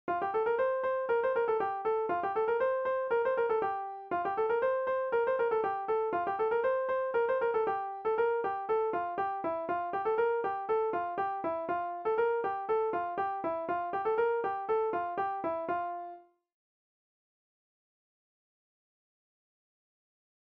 Instrumental
danse : branle